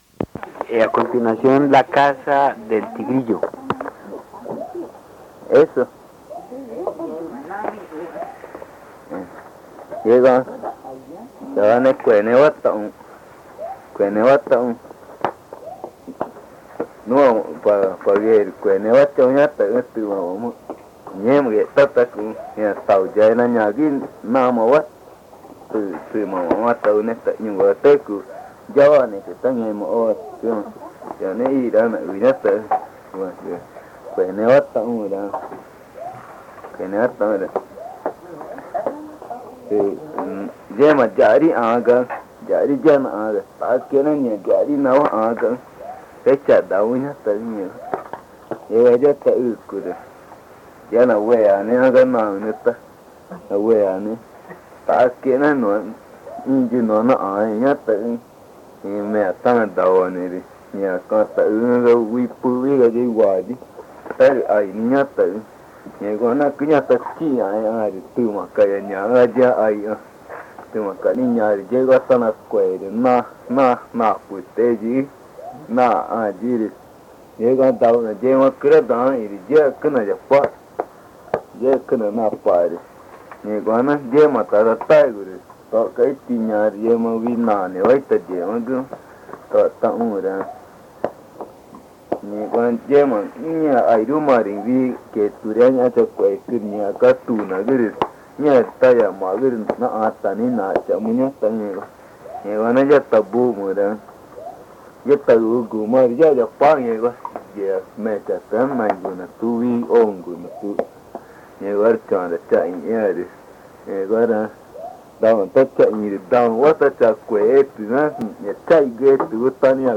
Río Amazonas (Colombia), problamente Pozo Redondo